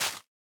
Minecraft Version Minecraft Version 1.21.5 Latest Release | Latest Snapshot 1.21.5 / assets / minecraft / sounds / block / big_dripleaf / break1.ogg Compare With Compare With Latest Release | Latest Snapshot
break1.ogg